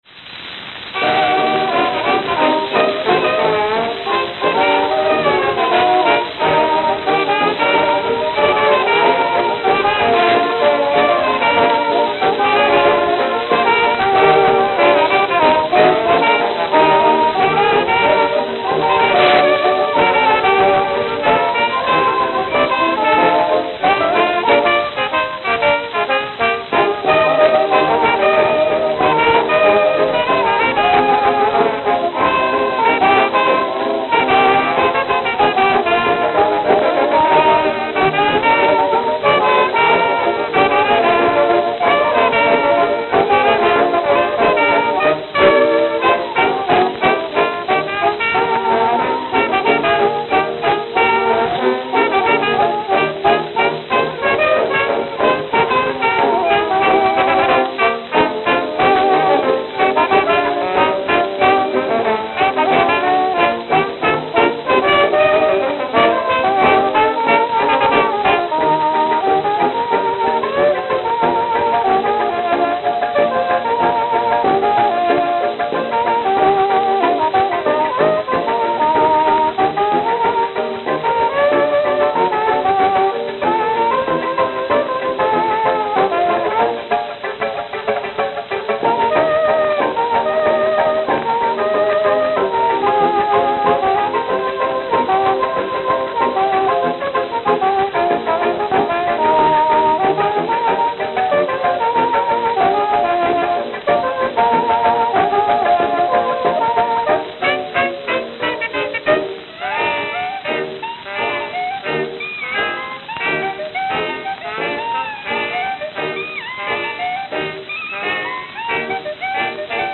Acoustical Recordings